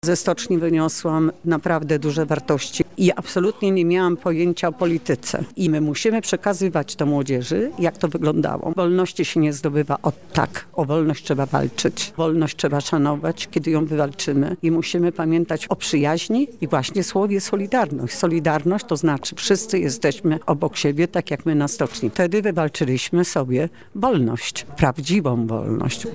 W ramach wydarzenia na UMCS, wszyscy zainteresowani mogli posłuchać wykładu o solidarności i funkcjonującej opozycji antysystemowej w czasach polskiego komunizmu. Henryka Krzywonos-Strycharska, organizatorka strajków gdańskich, mówiła o aktywności i roli młodych w kształtowaniu się zmian kiedyś i dziś.